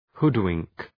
hoodwink.mp3